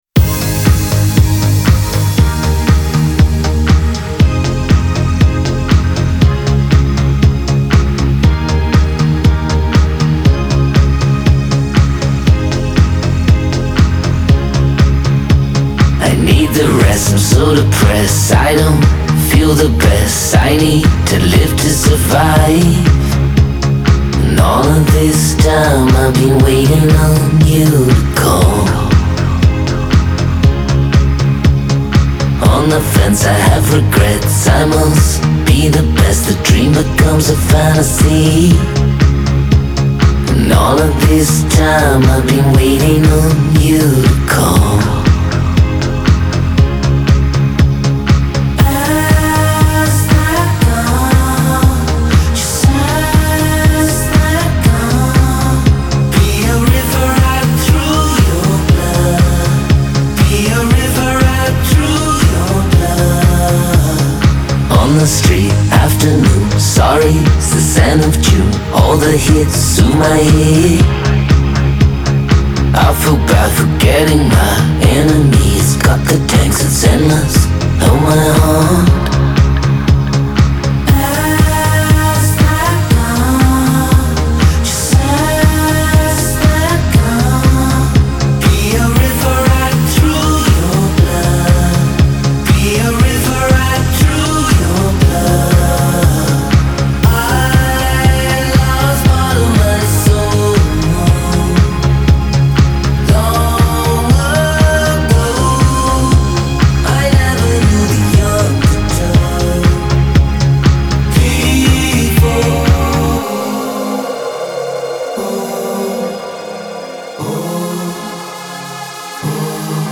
• Жанр: Pop, Electronic